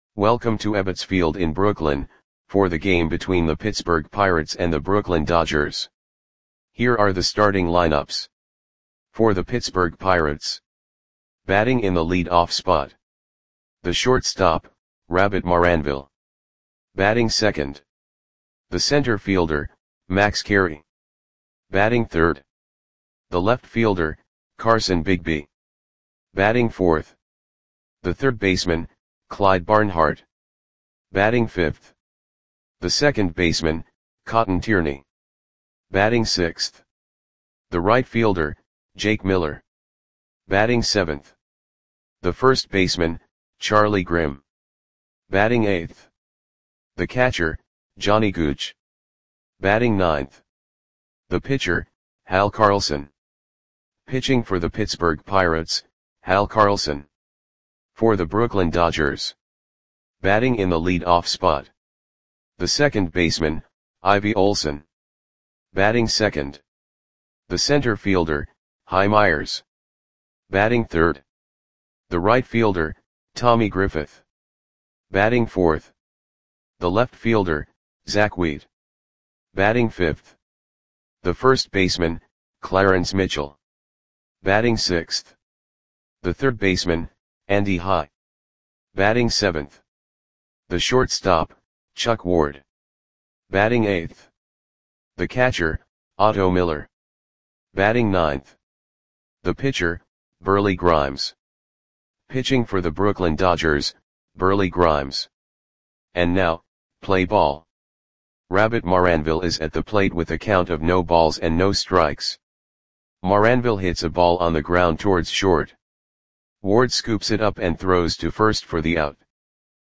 Audio Play-by-Play for Brooklyn Dodgers on July 16, 1922
Click the button below to listen to the audio play-by-play.